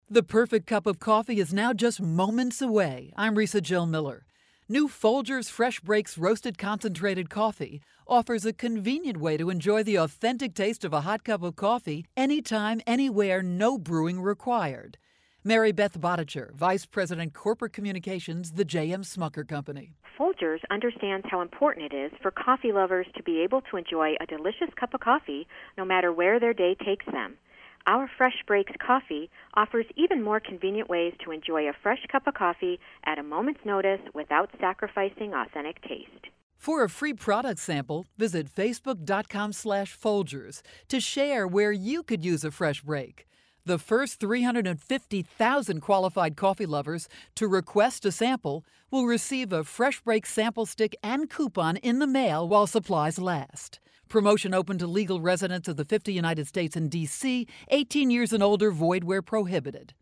March 18, 2013Posted in: Audio News Release